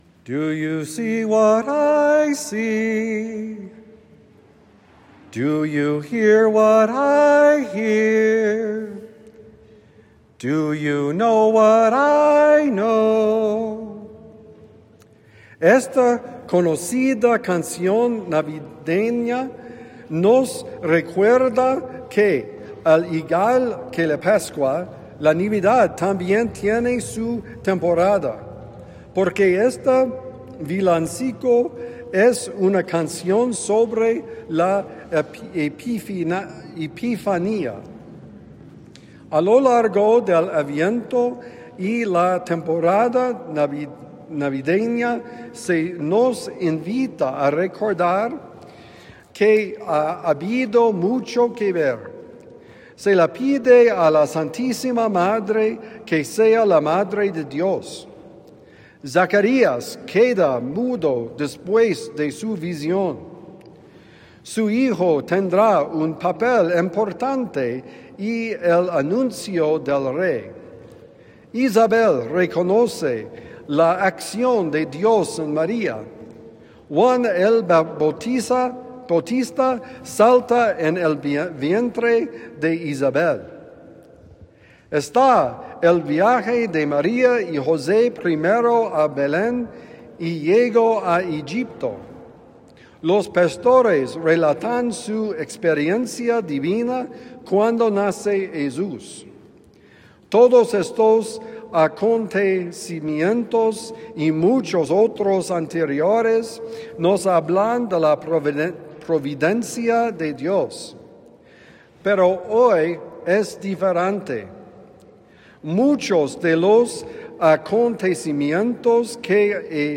¿Ves lo que yo veo? Homilía para el domingo 4 de enero de 2026